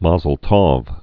(mäzəl tôf, tôv, tōv)